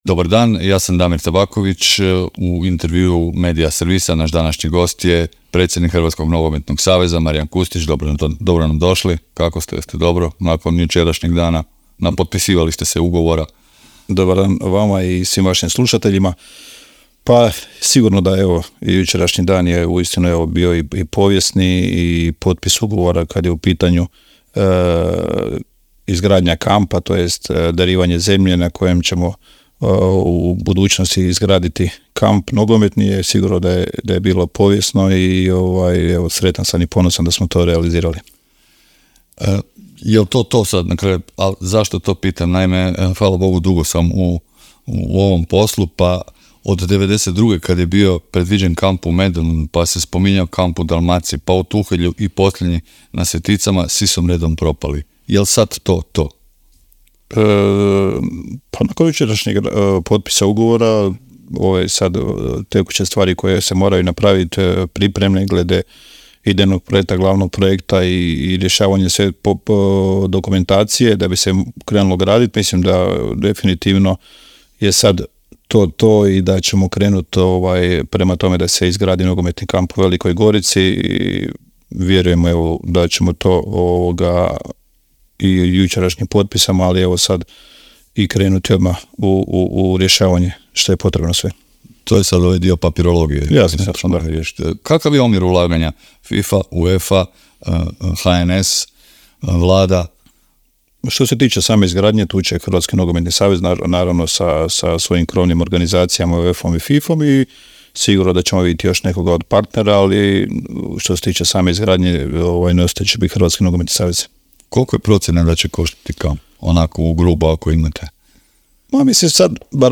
ZAGREB - Predsjednik Hrvatskog nogometnog saveza Marijan Kustić gostovao je u Intervjuu Media servisa dan nakon potpisivanja sporazuma o darovanju zem...